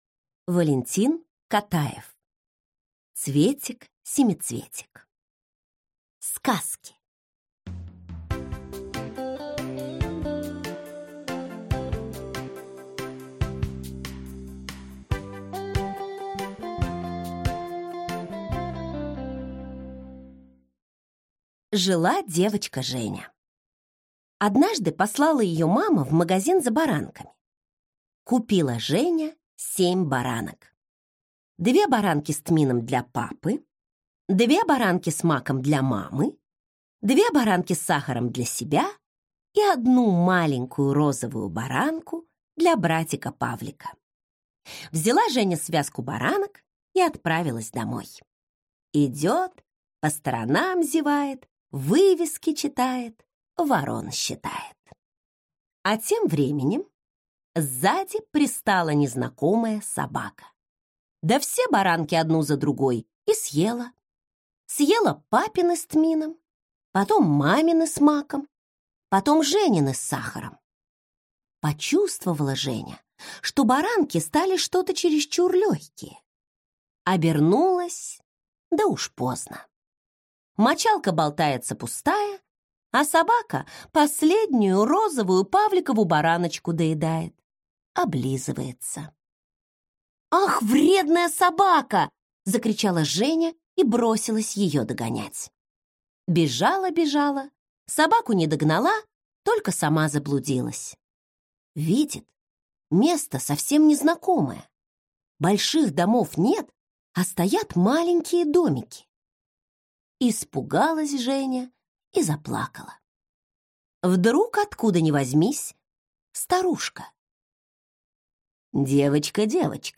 Аудиокнига Цветик-семицветик. Сказки | Библиотека аудиокниг